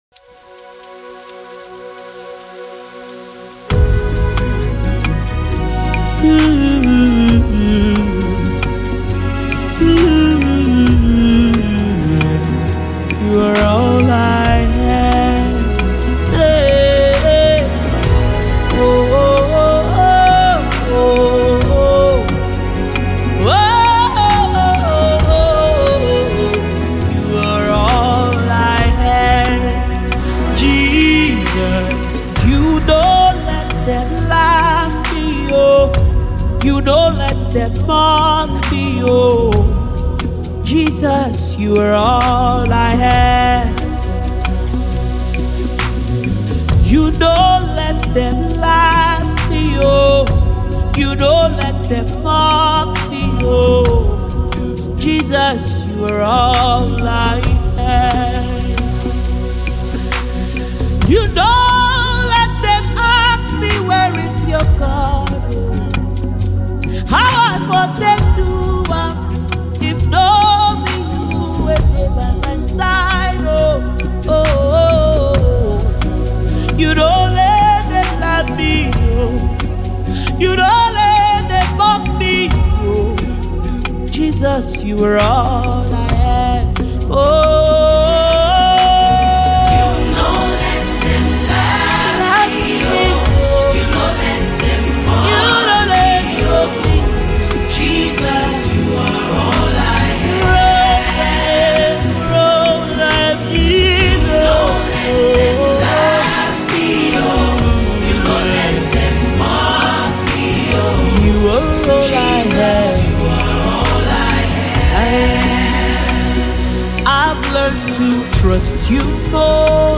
a soul-stirring gospel project with 10 powerful tracks
With her strong voice and heartfelt lyrics